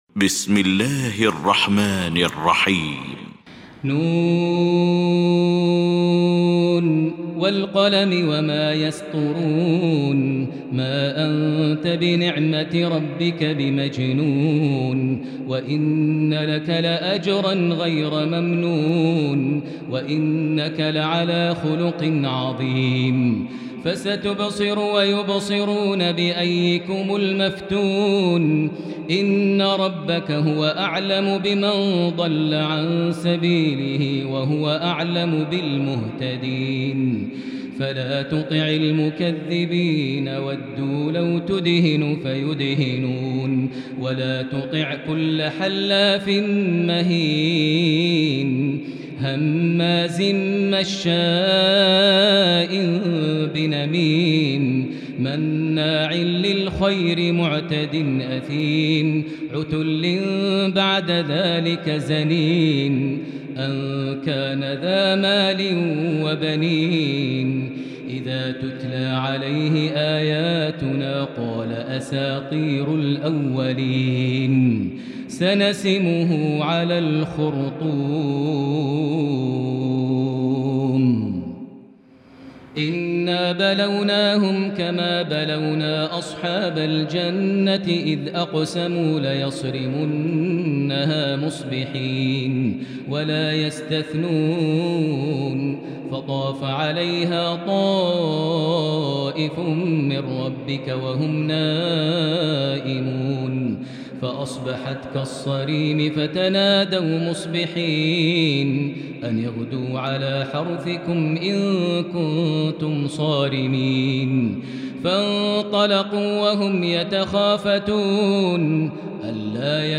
المكان: المسجد الحرام الشيخ: فضيلة الشيخ ماهر المعيقلي فضيلة الشيخ ماهر المعيقلي القلم